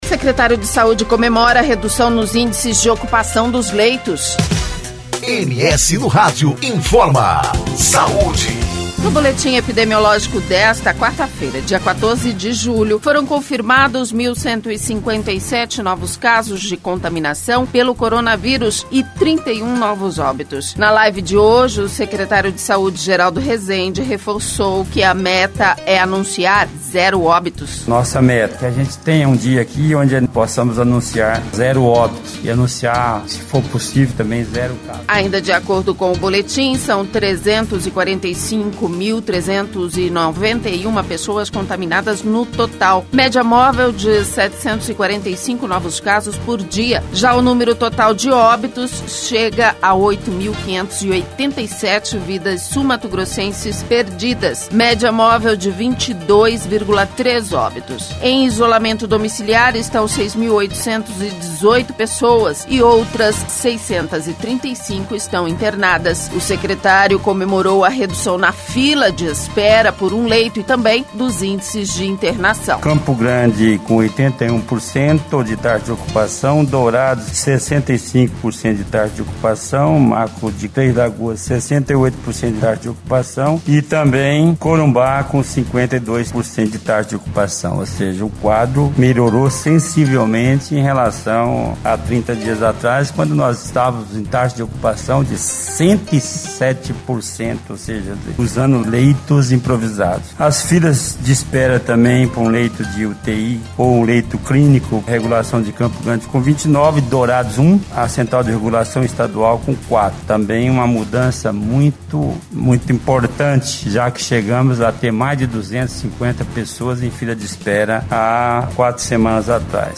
Na live de hoje, o secretário de saúde Geraldo Resende reforçou que a meta é anunciar zero óbitos.